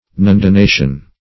Search Result for " nundination" : The Collaborative International Dictionary of English v.0.48: Nundination \Nun`di*na"tion\, n. [L. nundinatio.]